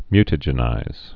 (mytə-jĕnīz, -jə-nīz)